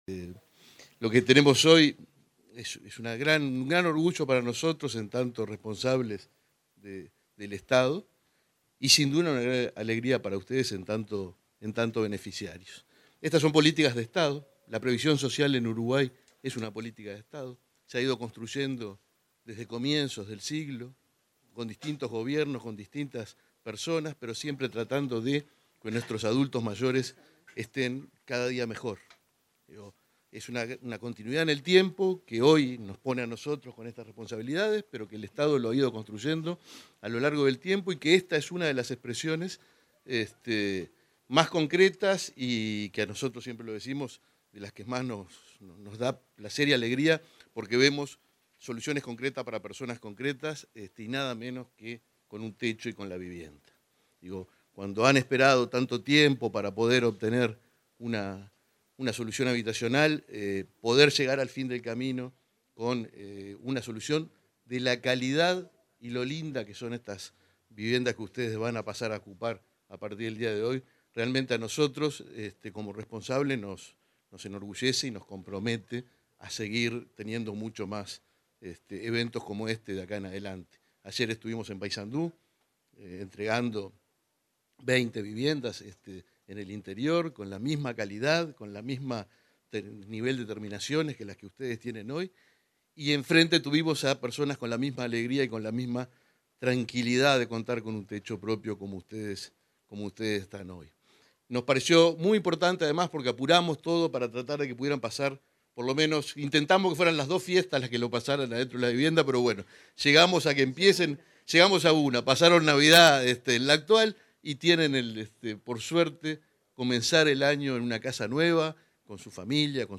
Palabra de autoridades en inauguración de viviendas en Montevideo